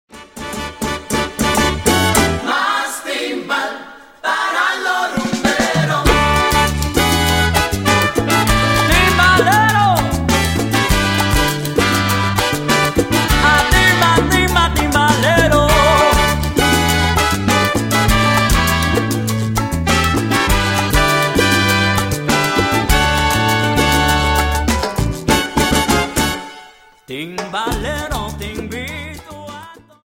Dance: Salsa 50 Song